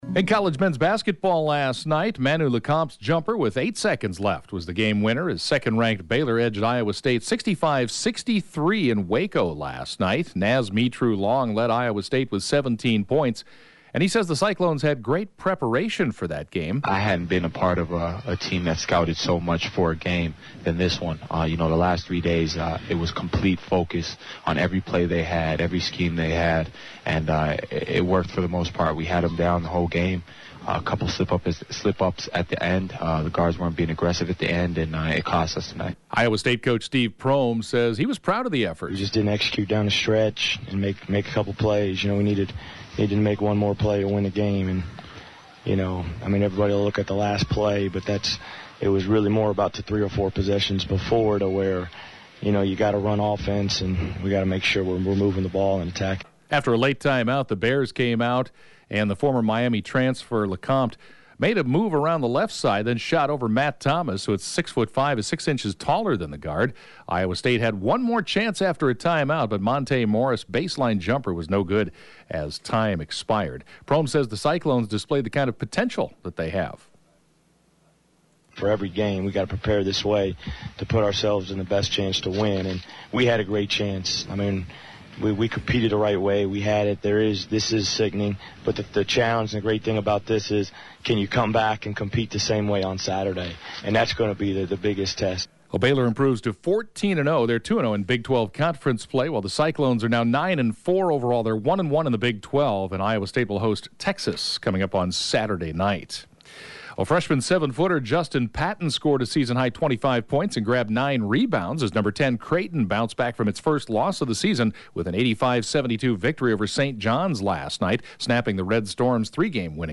(Podcast) KJAN Morning Sports report, 1/5/2017